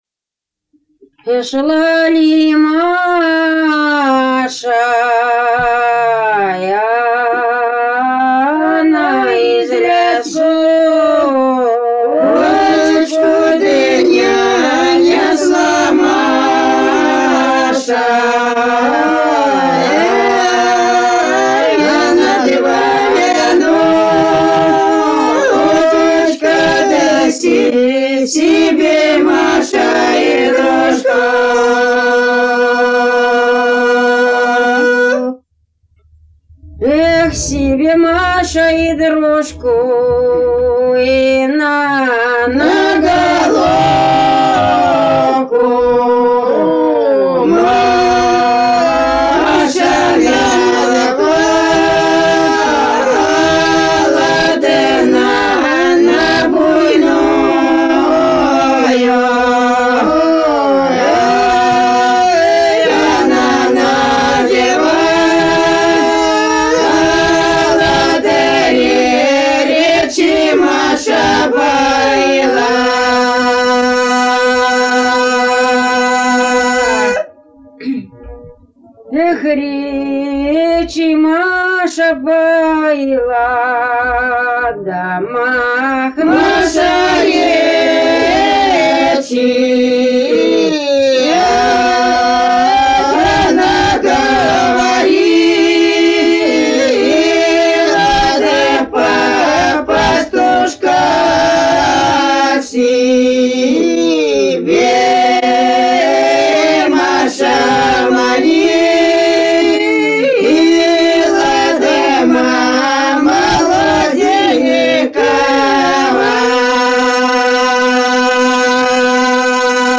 лирическая на Троицу